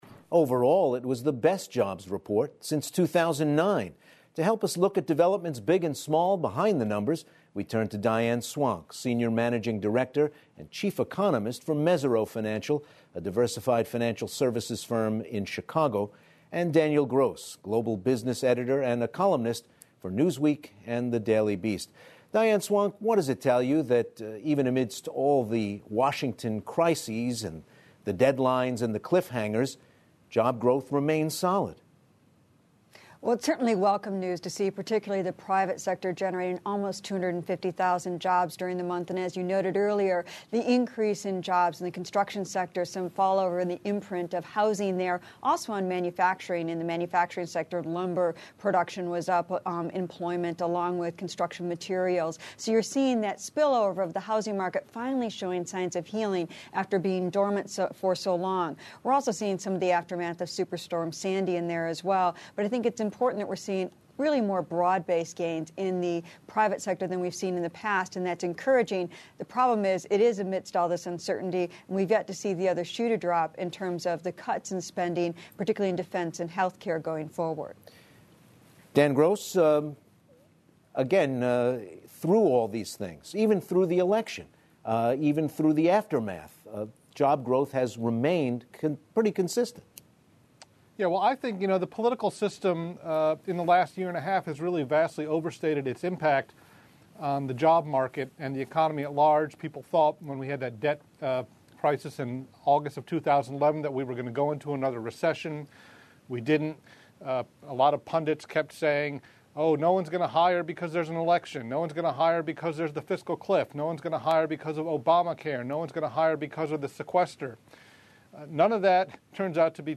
英语访谈节目:人才市场复苏,就业形势及收入将如何变化